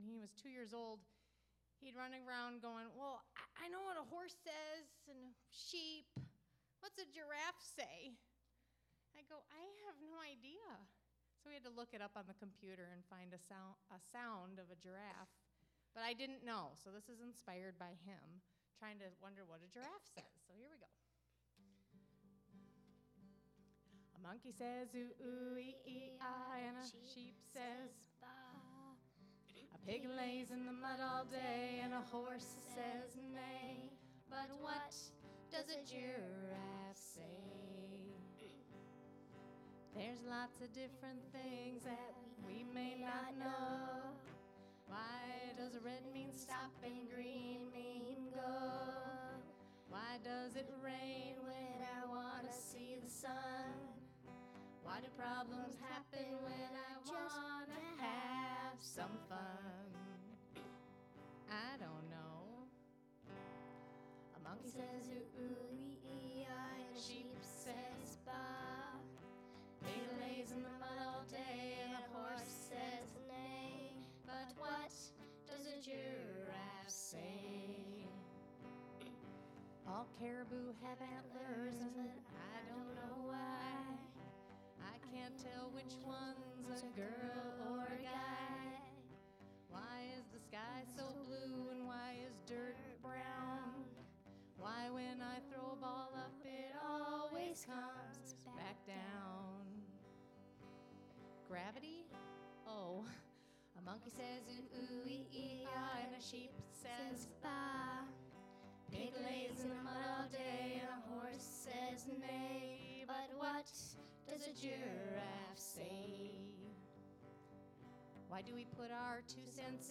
Original Alaskan Children's Songs